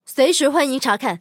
LT-35查看战绩语音.OGG